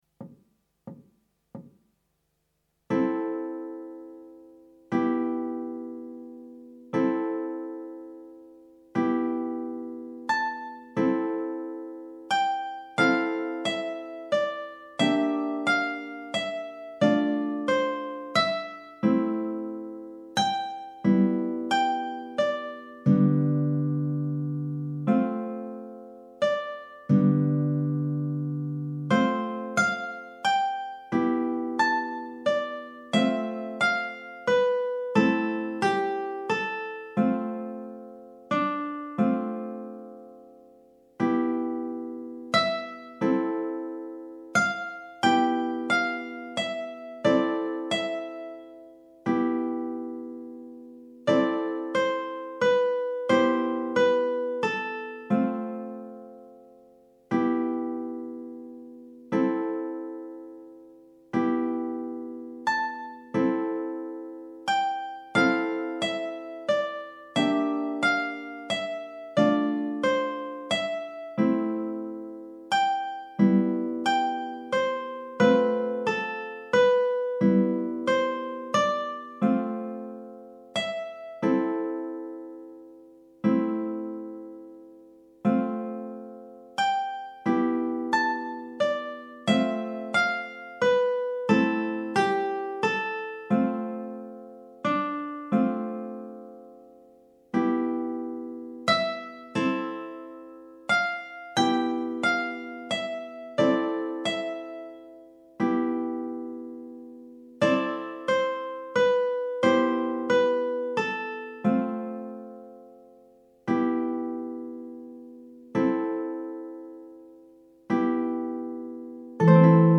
minus Guitar 3